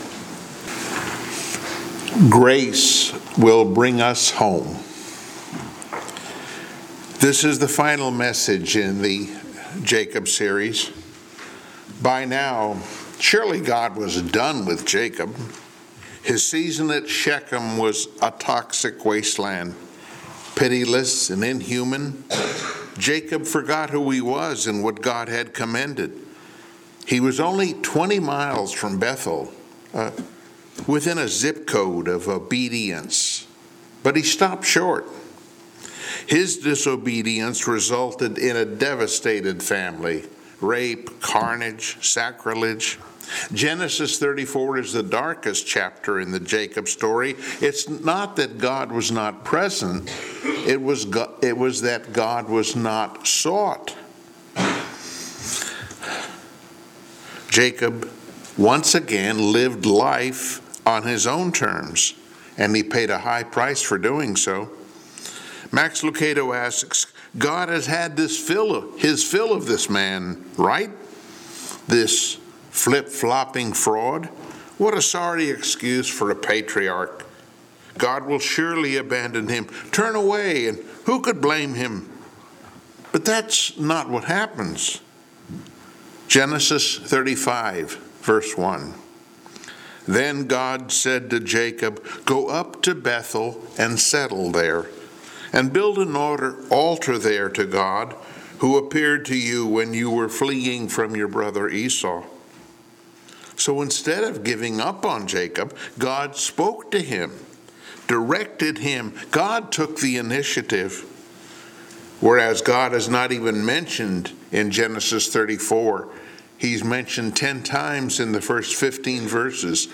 Passage: Genesis 35 Service Type: Sunday Morning Worship